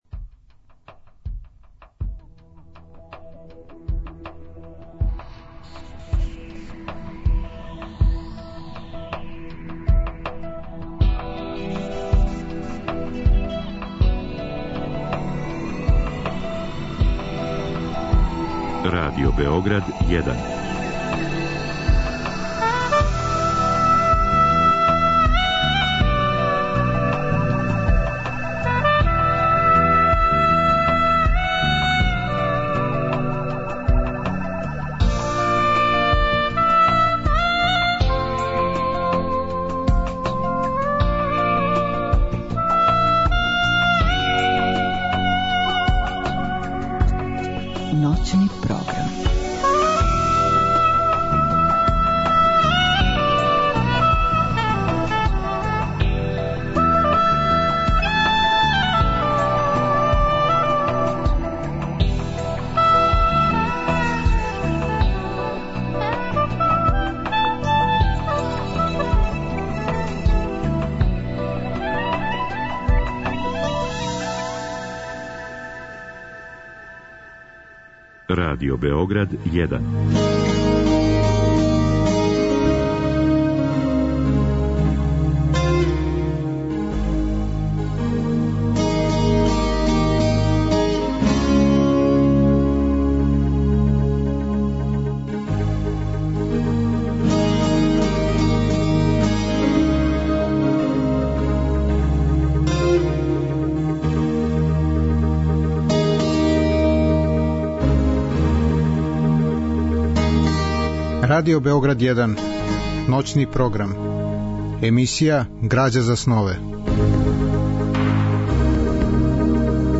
Гости су људи из различитих професија, они који су и сами ствараоци, и блиска им је сфера духа и естетике. Разговор и добра музика требало би да кроз ову емисију и сами постану грађа за снове.
Биће речи о књижевном делу Џемса Џојса, о утицају који је то дело имало на стваралаштво српских писаца, о путевима модернизације српске књижевности и о појединим ирским песницима. У другом делу емисије, од два до четири часa ујутро, слушаћемо одабране делове радио-драме Портрет уметника у младости, која је рађена по истоименом делу Џемса Џојса.